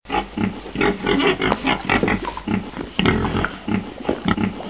Porc domestique
Sus scrofa domesticus
Le porc grogne.
porc.mp3